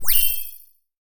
sci-fi_power_up_03.wav